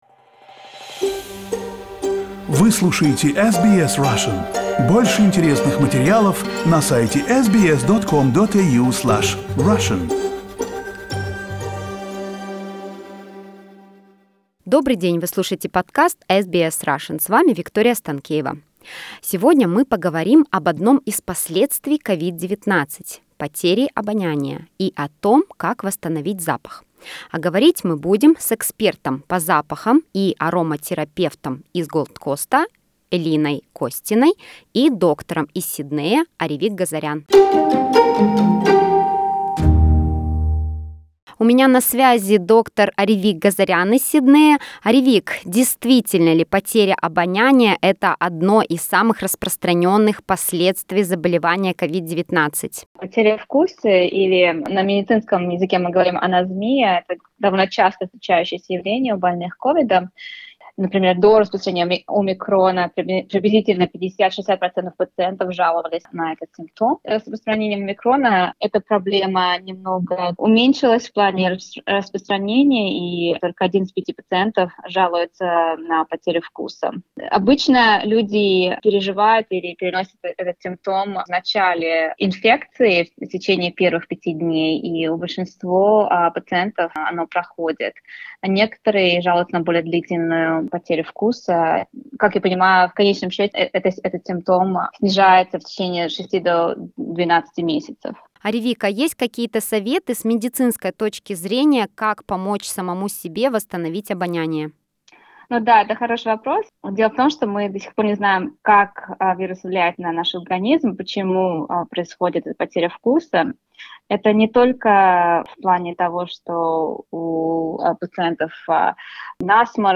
Interview with GP from Sydney